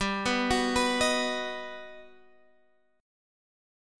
G+M7 chord